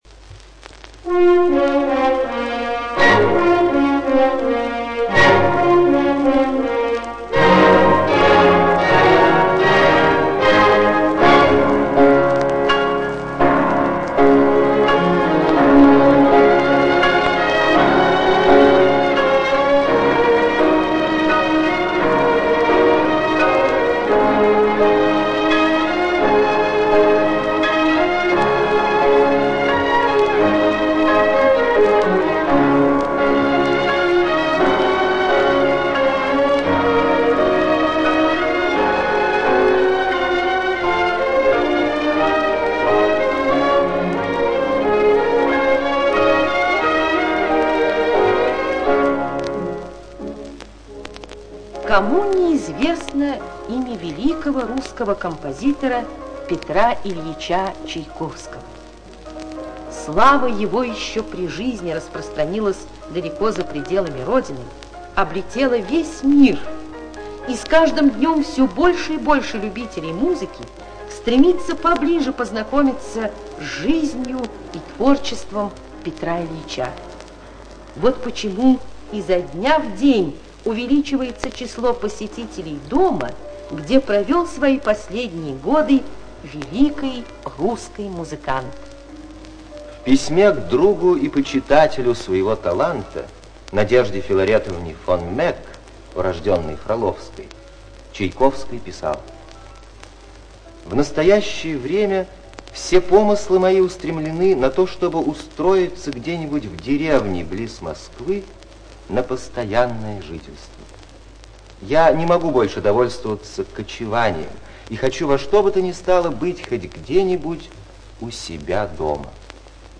ЖанрАудиоэкскурсии и краеведение